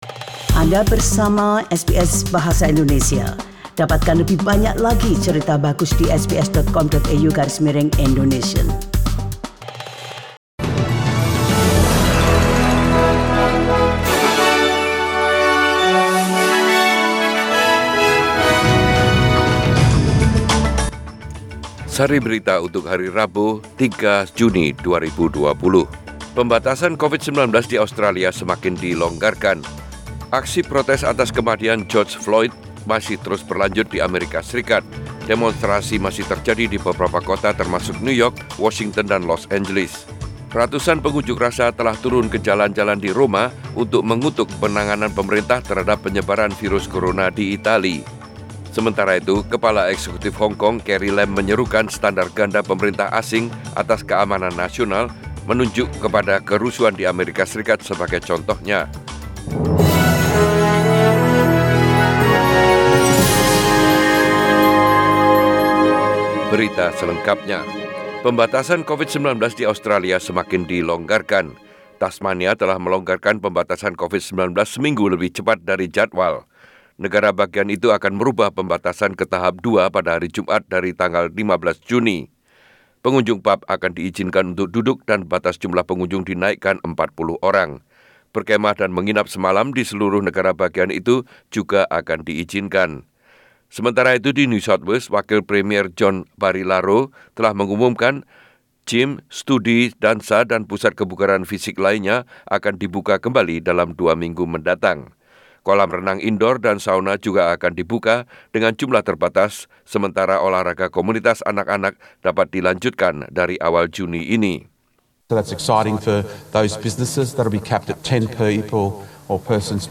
SBS Radio News in bahasa Indonesia - 03 June 2020